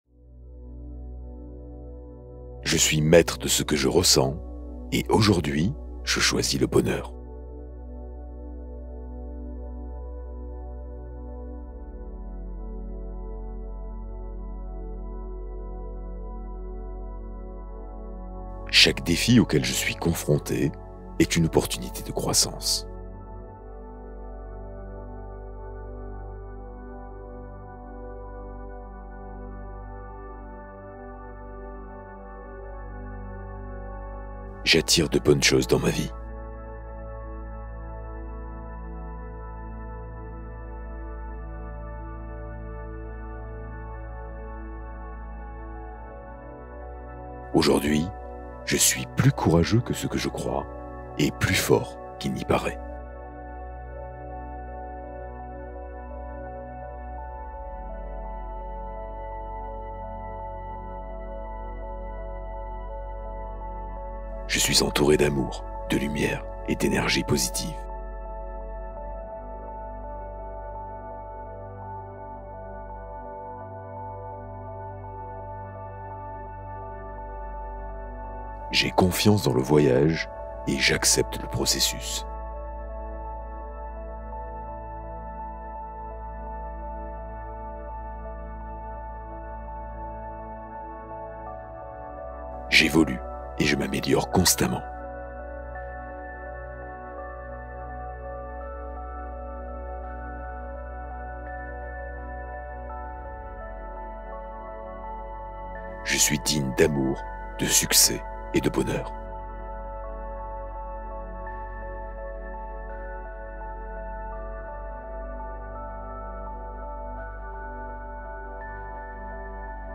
Fréquence 777 Hz : soutien vibratoire pour l’élévation intérieure